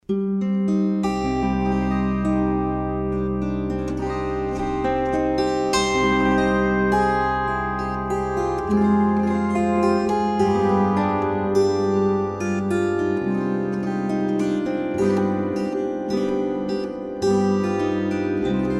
18 mélodies hébraïques à la cithare.
Musiques traditionnelles, adaptées pour cithare